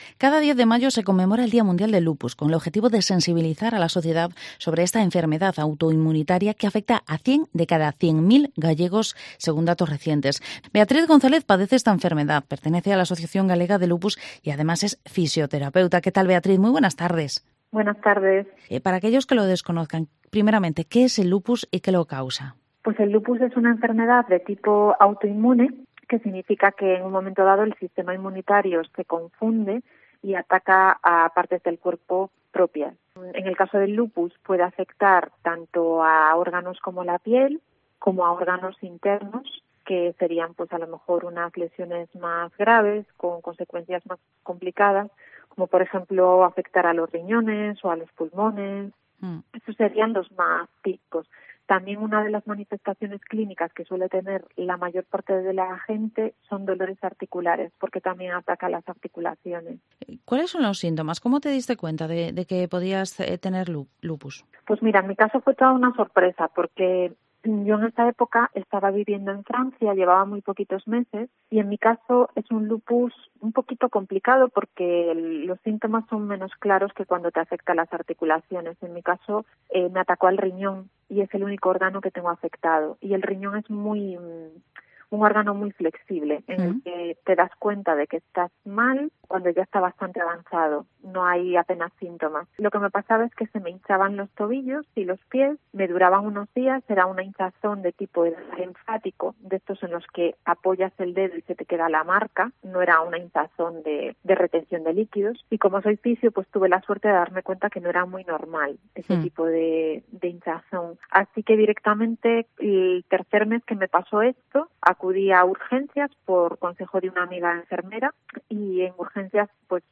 Vigo Entrevista Día Mundial del Lupus.